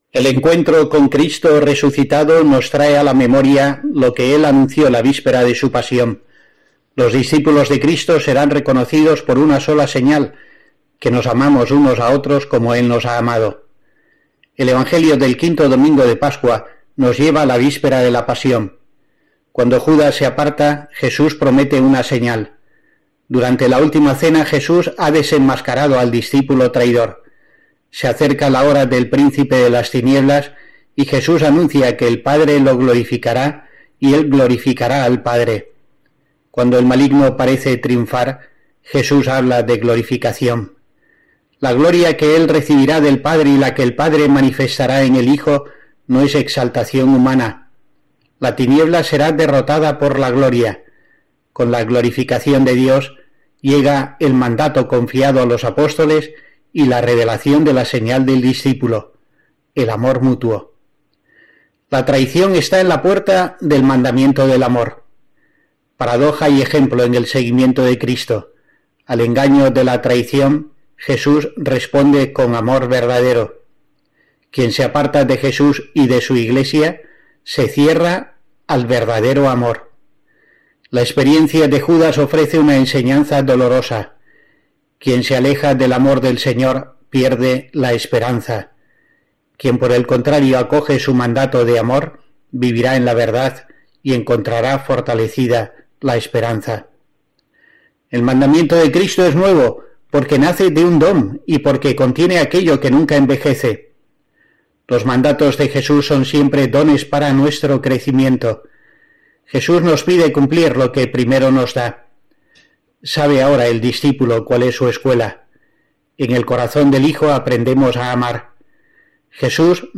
La reflexión semanal para COPE del obispo de Asidonia-Jerez abunda en el fundamento del V Domingo de Pascua
Escucha aquí la reflexión semanal de monseñor Rico Pavés para la programación socioreligiosa de COPE 13-05-22